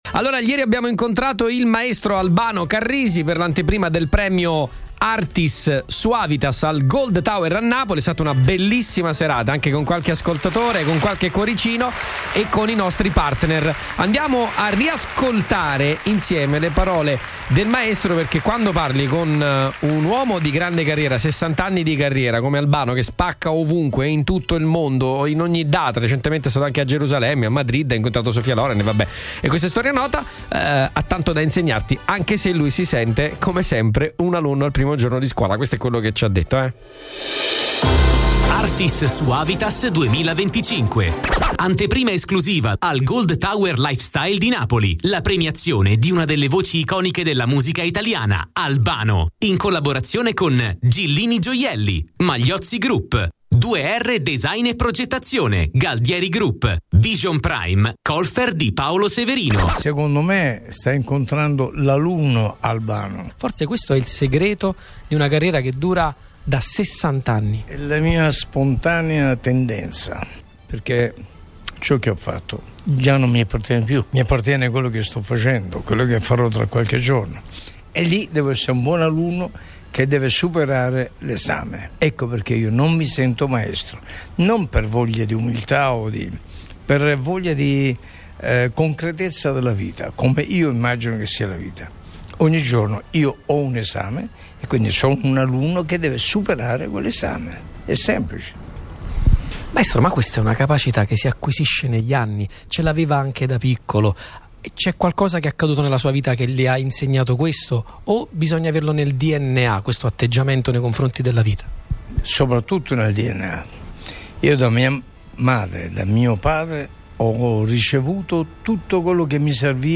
Un incontro denso di emozioni e significati quello tra Albano Carrisi e i microfoni di Radio Punto Nuovo, in occasione della sua premiazione con l’anteprima del Premio Artis Suavitas 2025. Una chiacchierata semplice e intensa, com’è nello stile del maestro. Riflessioni sulla musica, la vita, il talento, la fatica, la cultura.